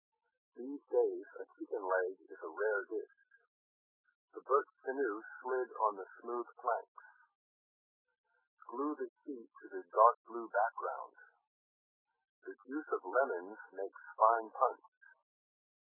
Lowering of frequencies above 1500 Hz with compression ratio=4 and offset=-100 Hz
After compression and decompression
by the VLC 8 codec at 12250 bps